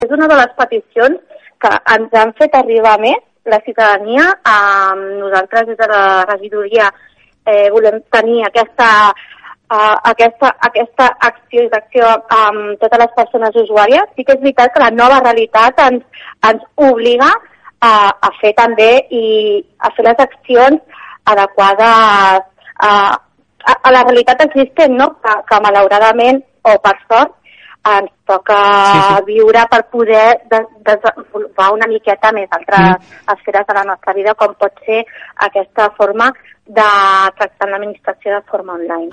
Segons ha explicat en una entrevista a aquesta emissora Cinzia Valbonesi, regidora d’Inserció Laboral de Malgrat de Mar, es tracta d’un total de 7 cursos, entre els quals s’inclou un curs de gestió de magatzem amb carnet de carretoner, iniciació a la llengua de signes, utilització del smartphone per cercar feina o una formació per conèixer els tràmits que es poden fer amb el certificat IdCat.